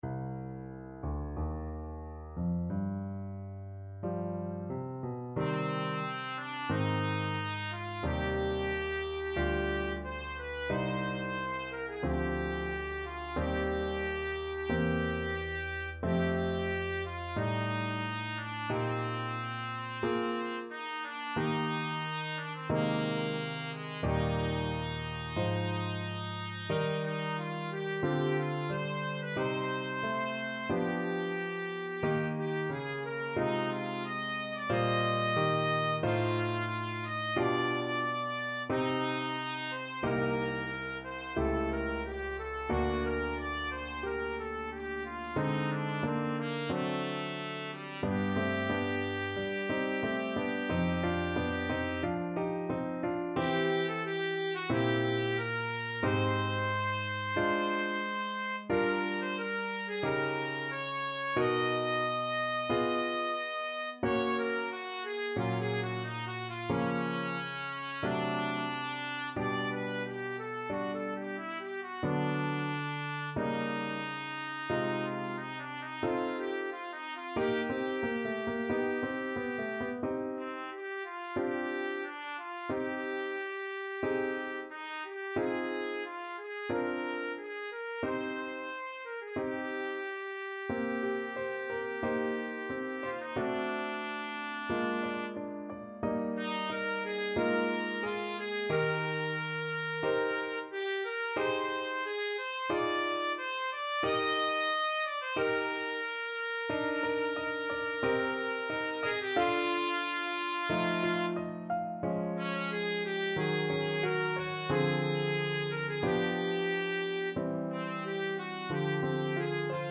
Trumpet
4/4 (View more 4/4 Music)
Adagio, molto tranquillo (=60) =45
C minor (Sounding Pitch) D minor (Trumpet in Bb) (View more C minor Music for Trumpet )
faure_piece_TPT.mp3